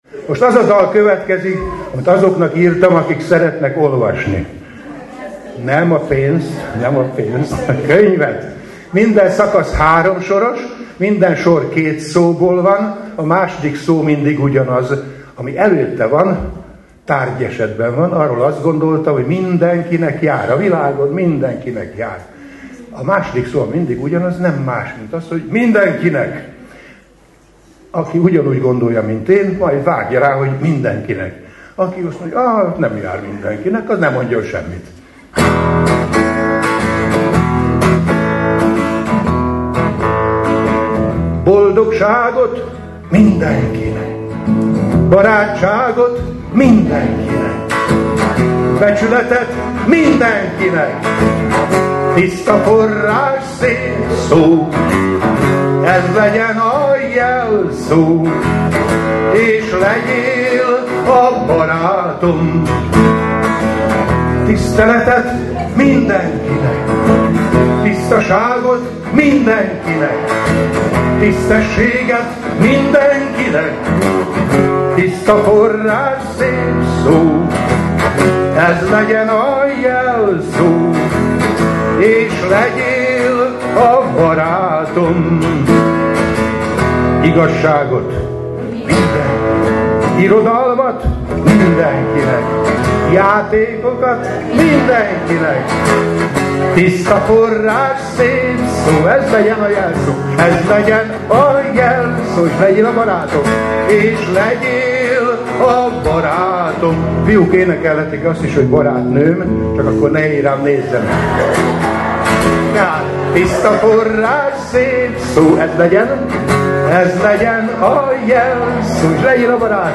Majd következtek a dalok, amelyek eléneklésébe a közönséget is bevonta, nem kis sikerrel, ekképpen: